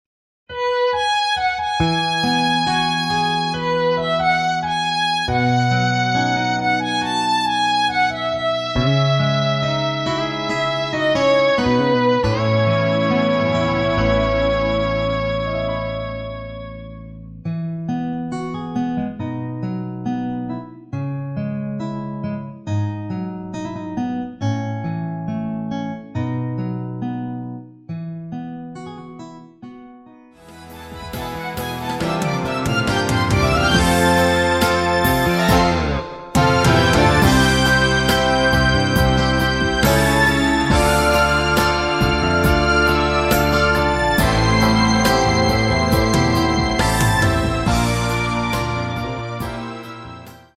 MR입니다.
원곡의 보컬 목소리를 MR에 약하게 넣어서 제작한 MR이며